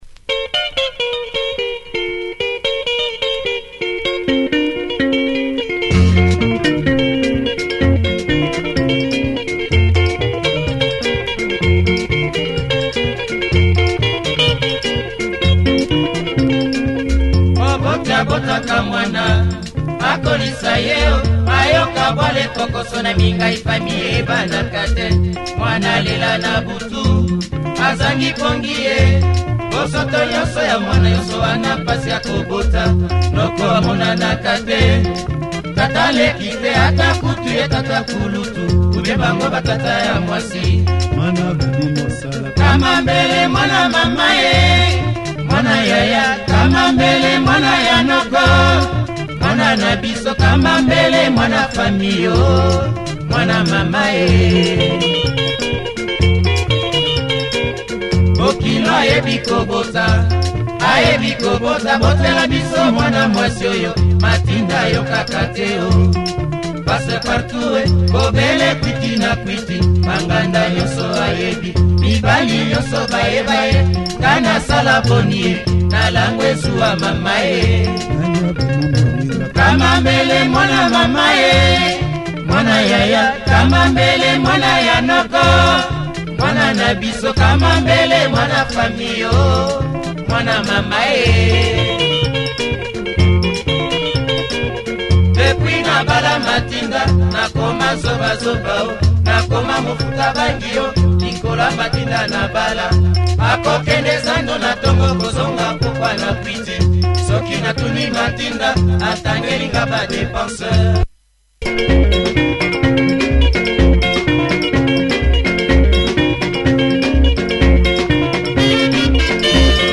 Nice modern Lingala